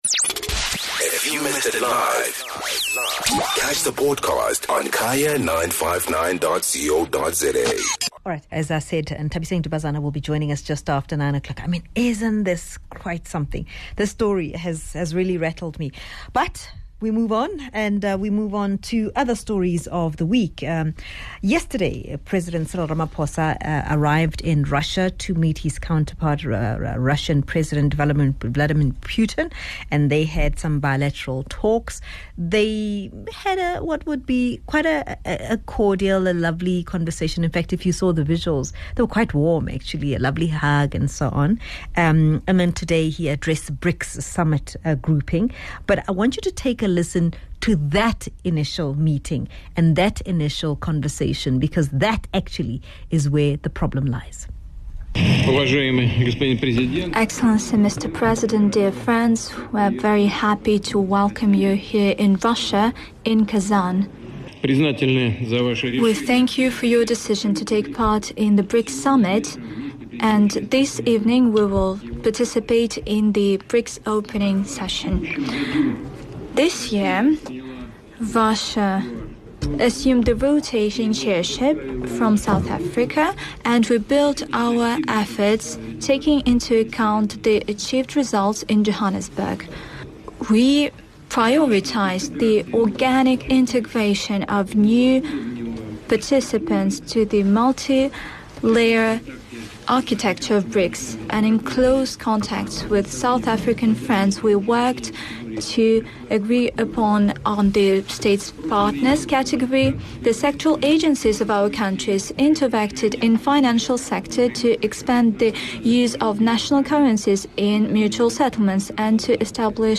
speaks to DA Spokesperson Willie Aucamp.